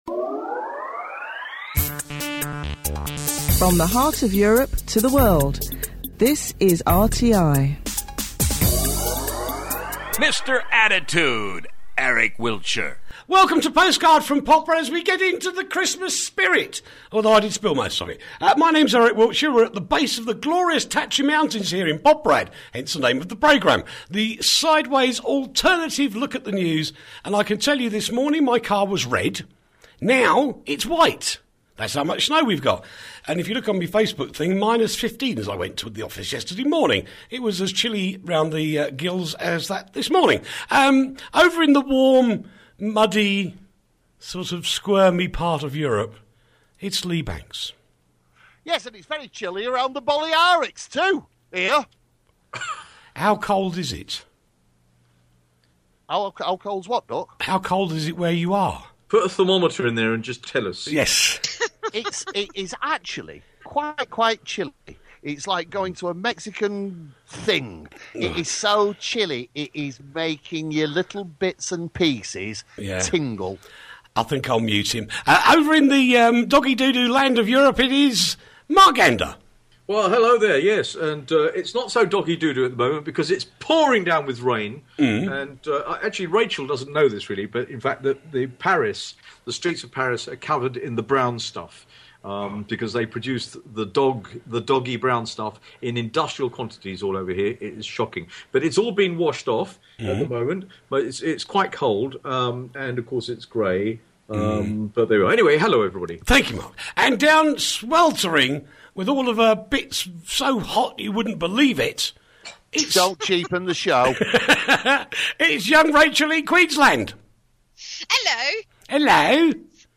Postcard From Poprad the alternative news show from Radio Tatras International